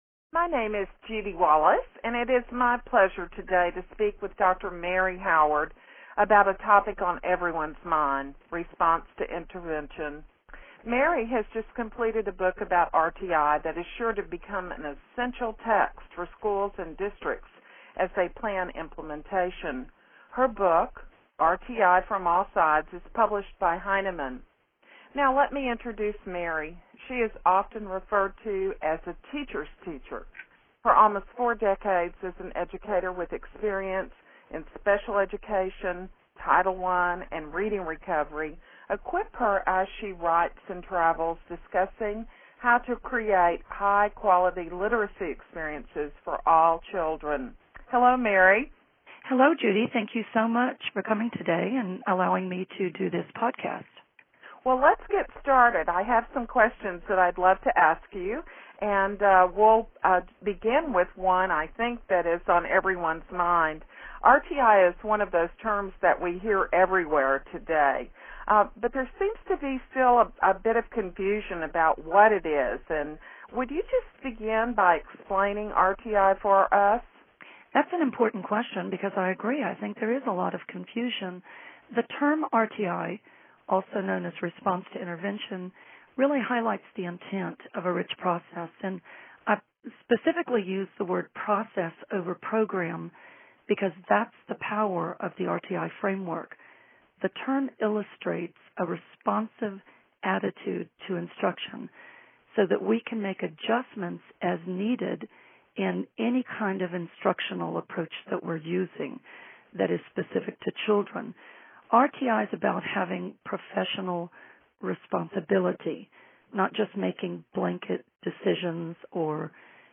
Conversations About Catching Young Readers Before They Struggle